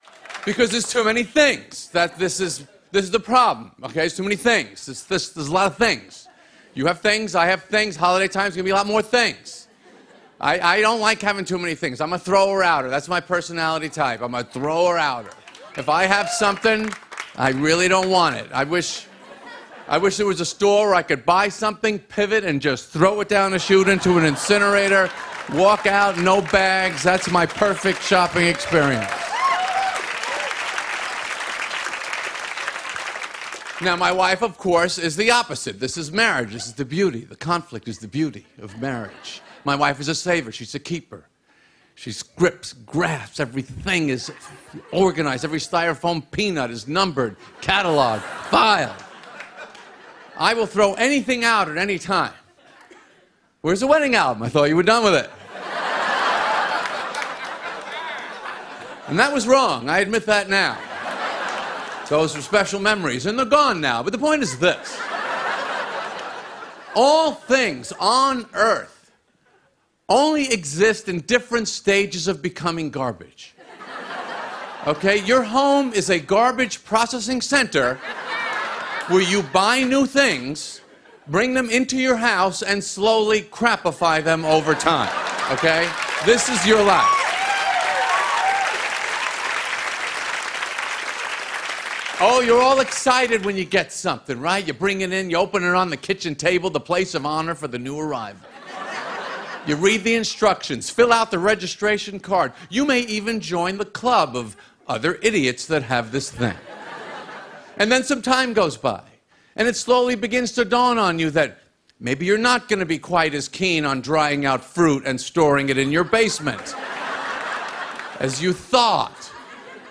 jerry-seinfeld-theres-too-many-things.mp3